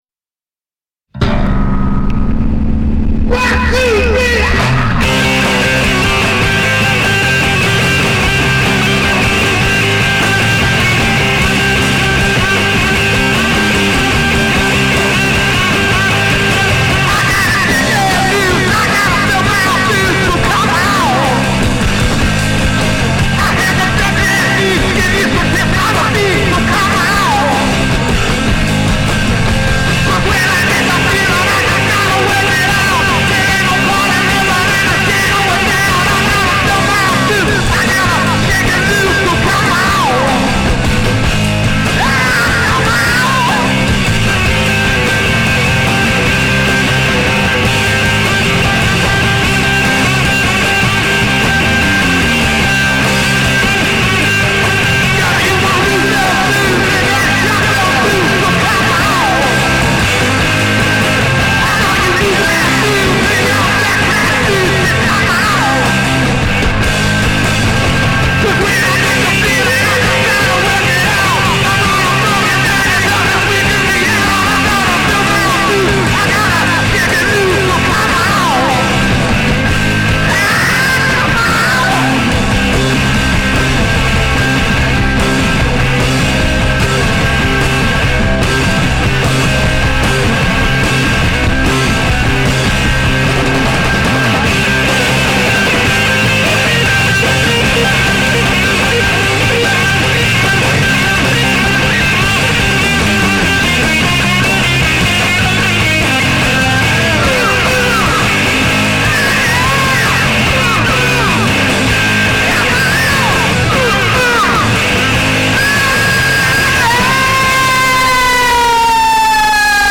ソウルやブルースのフィーリングを内包したハイエナジーなロックンロールがとにかく最高！
ガレージ名コンピ”Nuggets”からフリーやクリームまでを髣髴とさせる、最高のサイケデリック・ロック！